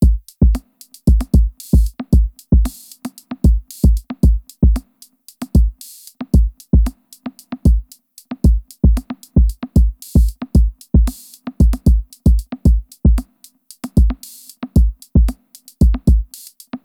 bpm: 114
Kit: BABALOG (Default)
In the example BT is used instead of BD, LT in place of SD as the lower machines are set up as bass.